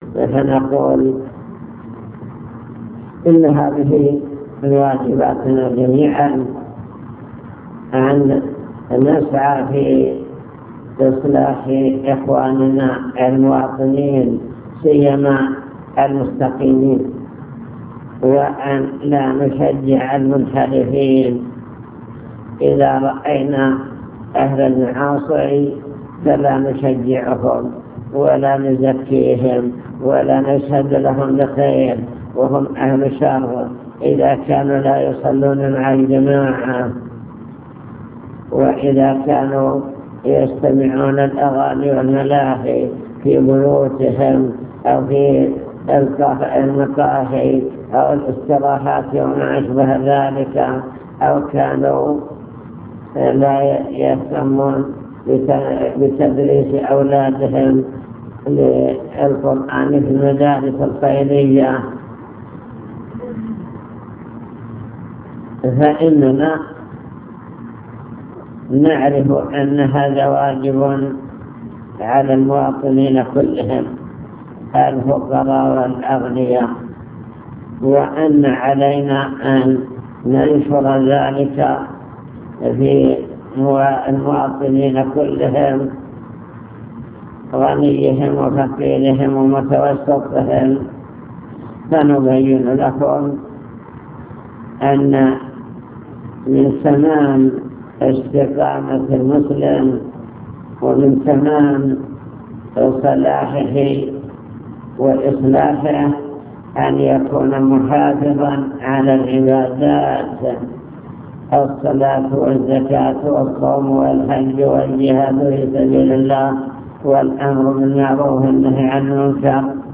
المكتبة الصوتية  تسجيلات - لقاءات  كلمة مجلس مستودع الأوقاف